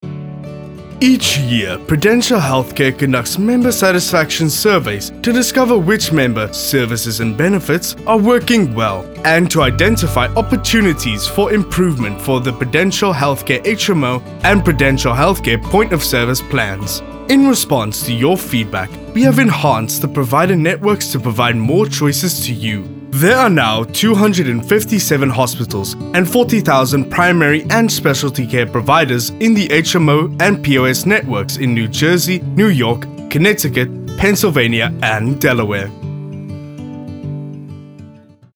South Africa
dramatic, friendly, warm
My demo reels